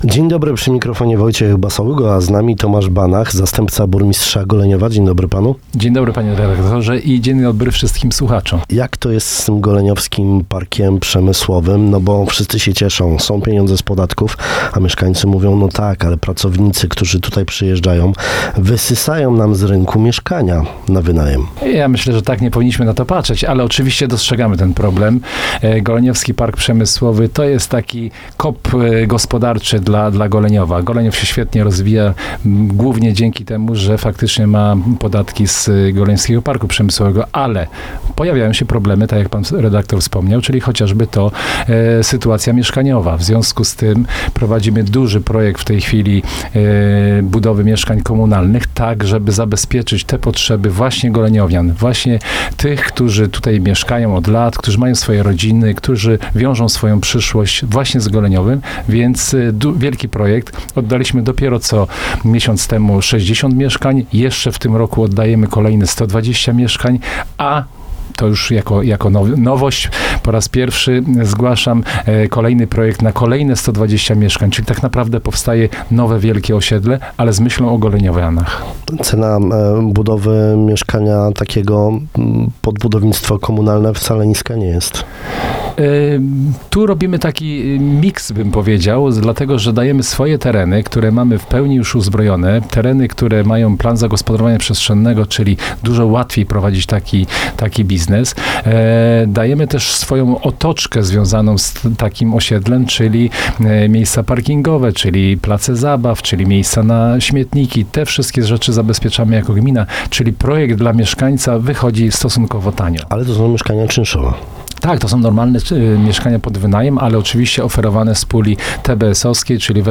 To tu miałaby się odbywać szybka naprawa, rebranding i powrót na linie – mówił o tym dziś rano Tomasz Banach, zastępca burmistrza Goleniowa.